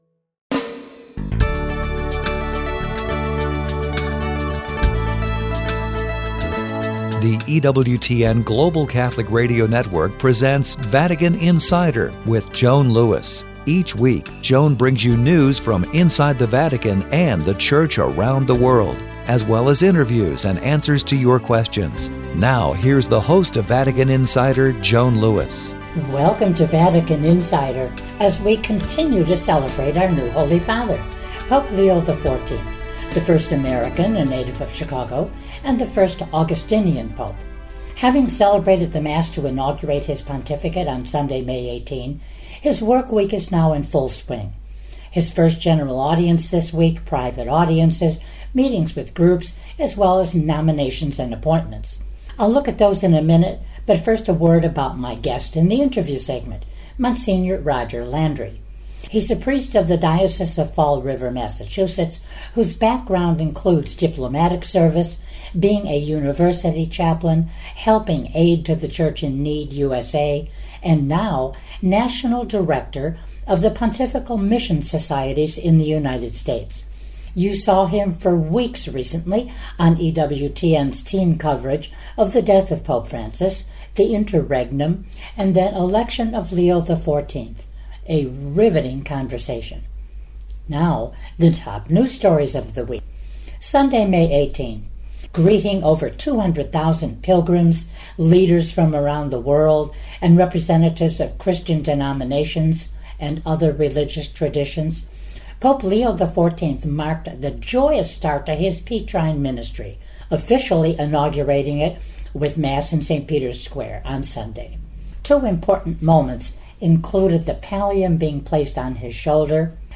1 Bishop Sheen Presents - THE IDENTITY CRISIS - The Bishop Sheen Program 24:24 Play Pause 3h ago 24:24 Play Pause Play later Play later Lists Like Liked 24:24 Please enjoy this reflection from Fulton J. Sheen that was recorded for his popular television program called The Bishop Sheen Program.